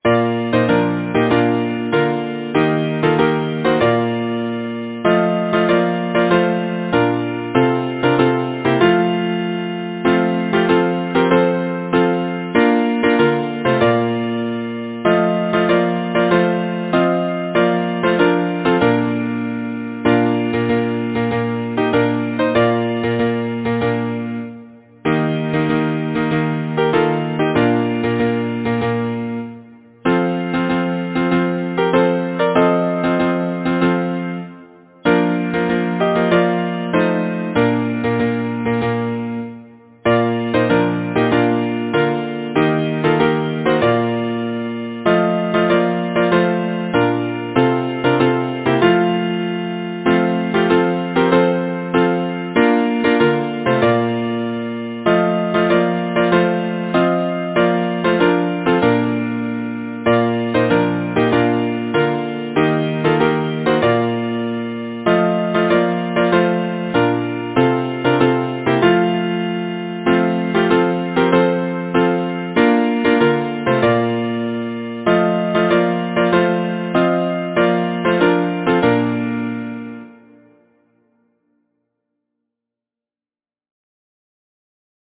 Title: Winter Glee Composer: James Monroe Hagan Lyricist: Ida Lilliard Reed Number of voices: 4vv Voicing: SATB Genre: Secular, Partsong, Glee
Language: English Instruments: A cappella